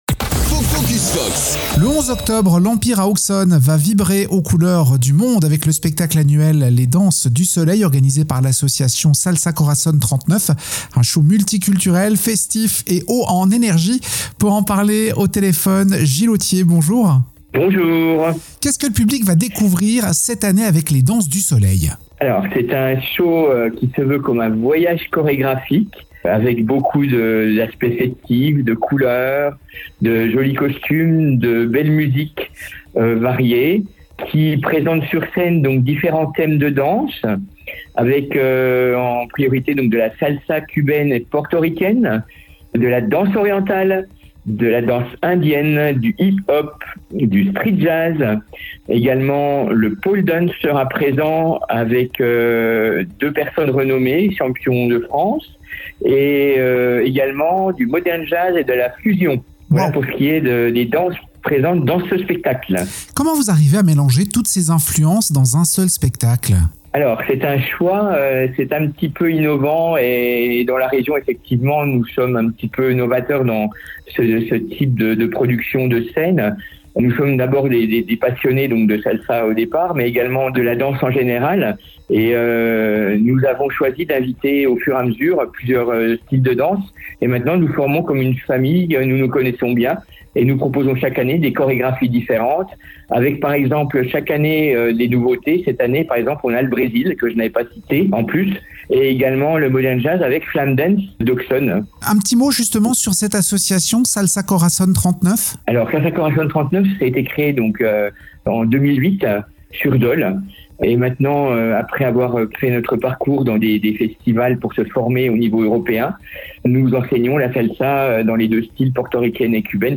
Pour en parler, nous sommes au téléphone avec